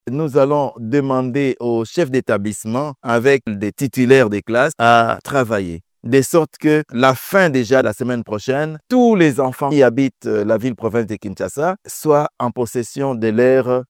Suivez les propos d'Hubert Kimbonza: